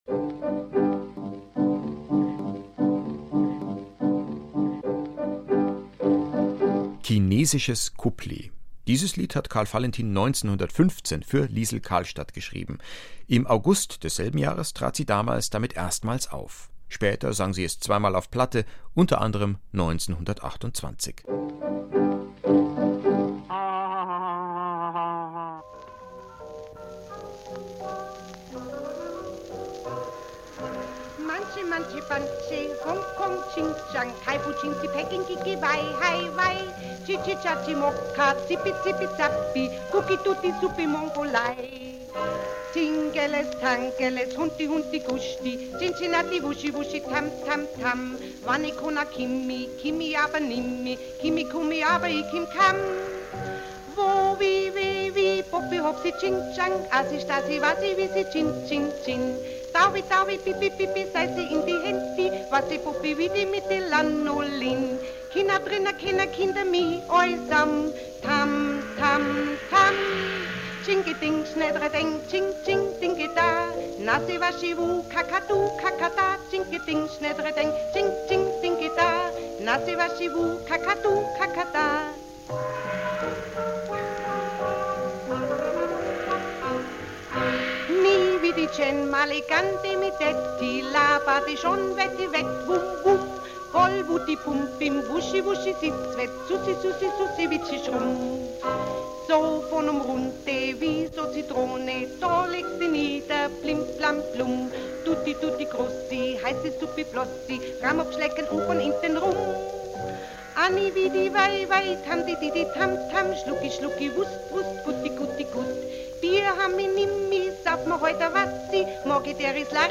..und weil nicht ohne geht, ein Juwel, geschrieben von Valentin und gesungen von Liesl Karlstadt